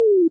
tone_down_shorter_faster.ogg